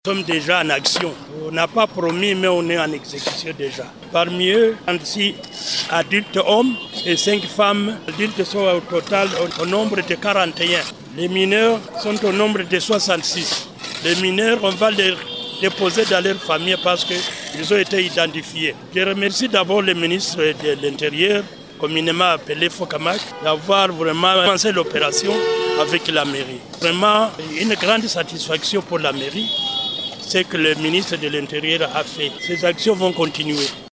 Le maire de Bukavu Zénon Karumba en entretien avec Radio Maendeleo à son bureau de travail.